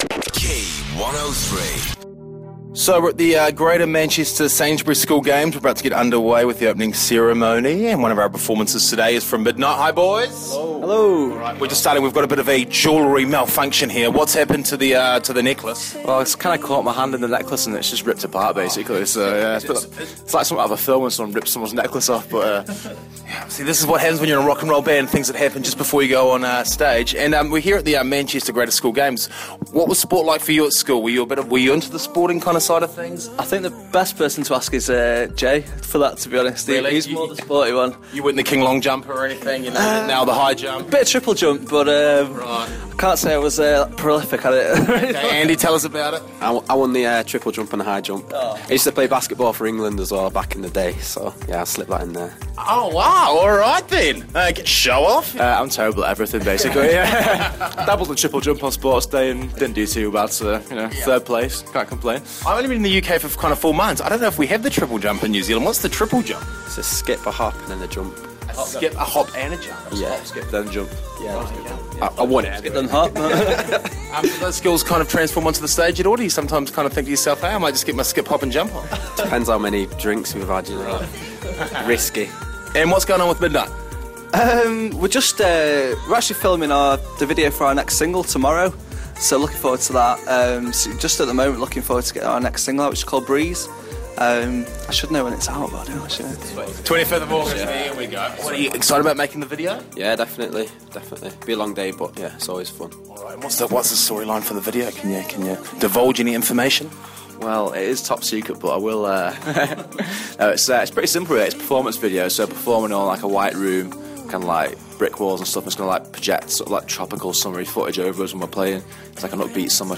MDNGHT GM School Games interview 15-04-14